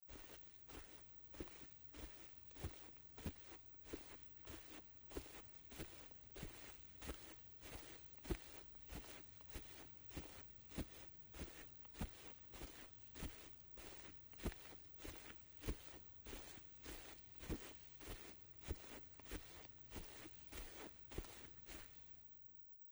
在薄薄的积雪中轻轻的行走－YS070525.mp3
通用动作/01人物/01移动状态/02雪地/在薄薄的积雪中轻轻的行走－YS070525.mp3
• 声道 立體聲 (2ch)